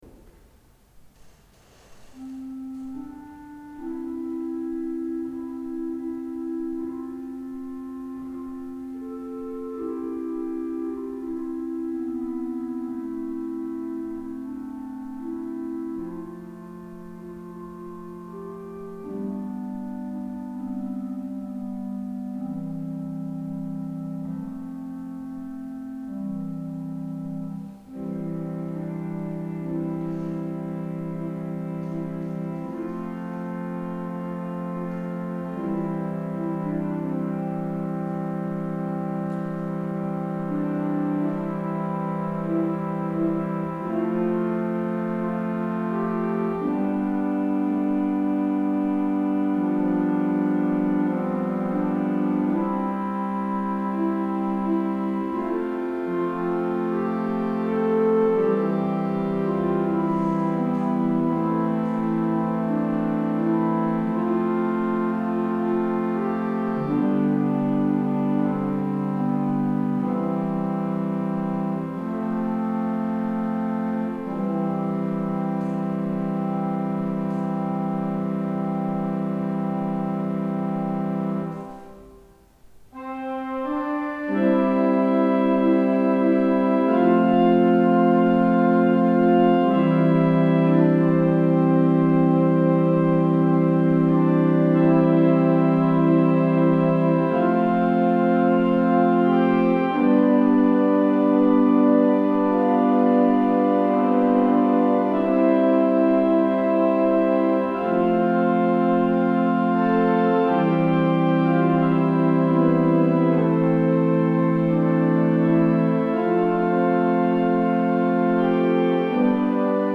Enkle akkorder og delvis keyboard teknikk.
Venheim Orgel
Orelund Kapell   ZOOM H4n 02.05.2012